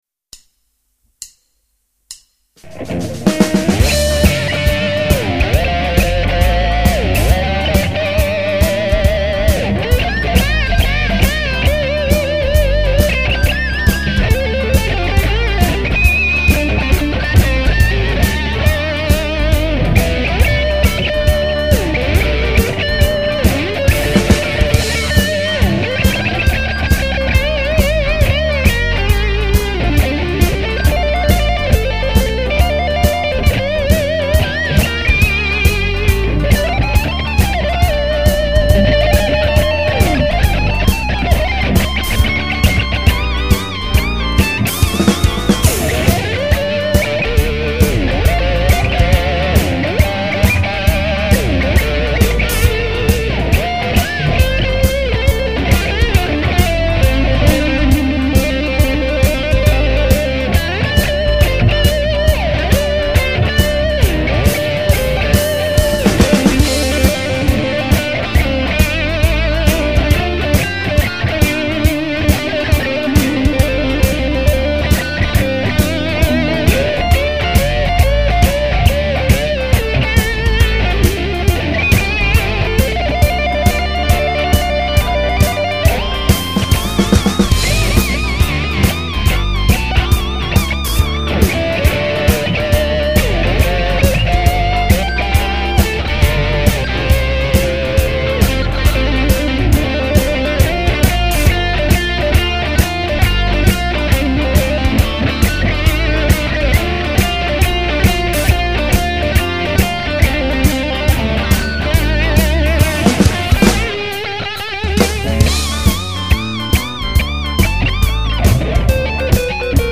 J'ai eu l'heureuse surprise d'entendre des gens tres talentueux ici, et en attendant mon site web, je me permet de vous proposer un mp3 en guise de carte de visite, d'un blues funk enregistré en une prise, avec ma Fender Strat Classic' 70 et un Fender Twin Deluxe....
Sympa en tout cas, ca fais bouger.
quand je dis résolument moderne c'est que ça sonne plus SRV que Elmore James, c'est pas un jugement